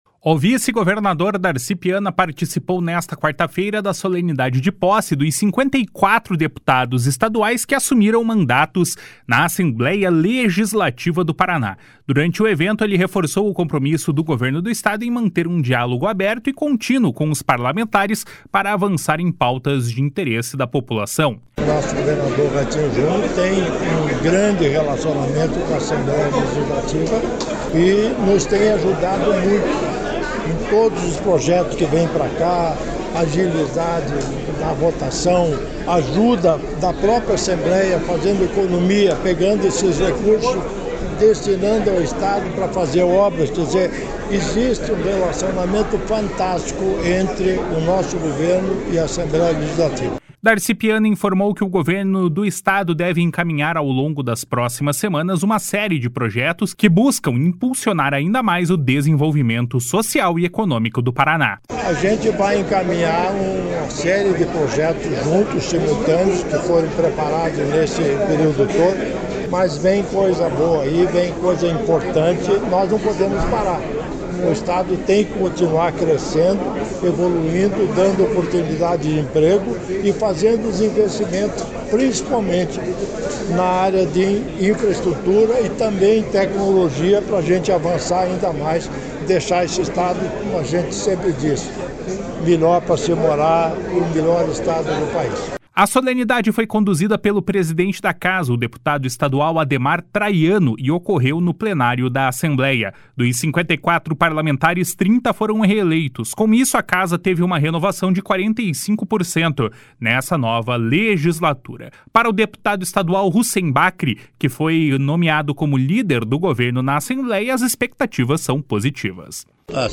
O vice-governador Darci Piana participou nesta quarta-feira da solenidade de posse dos 54 deputados estaduais que assumiram mandatos na Assembleia Legislativa do Paraná.
A solenidade foi conduzida pelo presidente da Casa, deputado estadual Ademar Traiano, e ocorreu no plenário da Assembleia.